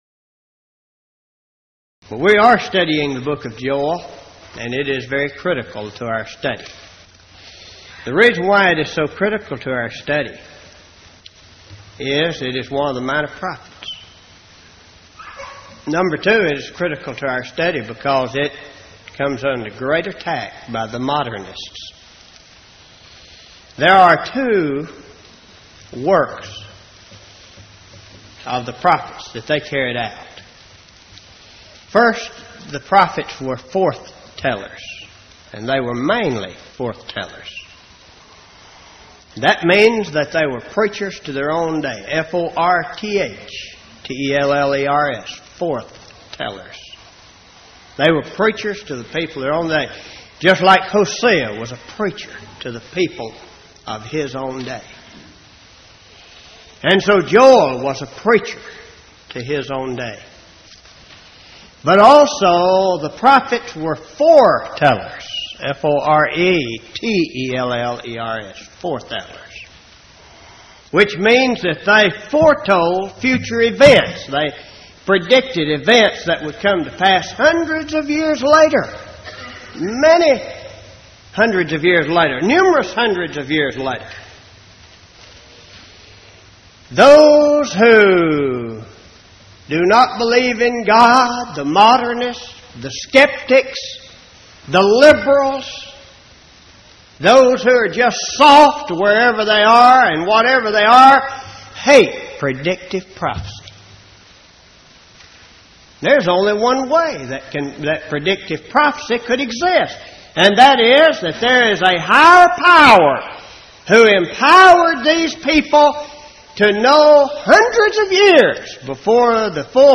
Event: 1990 Power Lectures
lecture